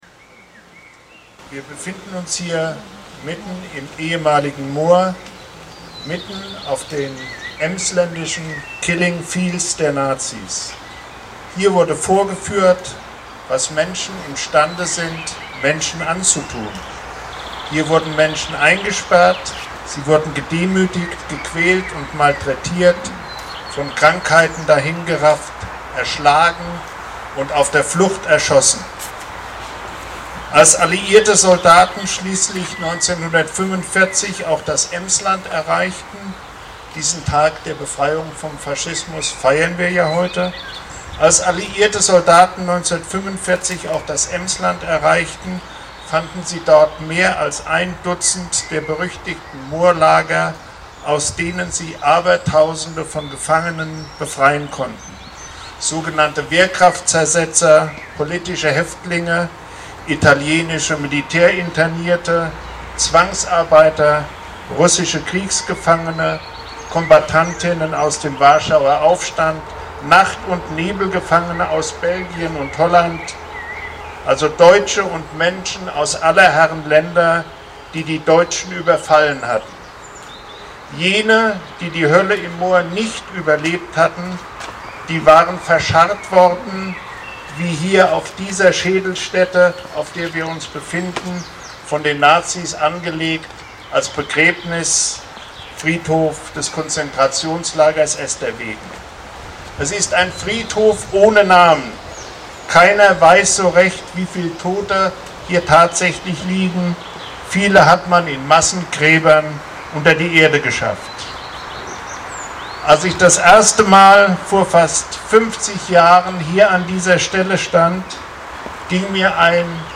Inzwischen gibt es eine Gedenkstätte, was Gerhard Kromschröder 2009 noch nicht wissen konnte. Hier ist seine Rede von damals.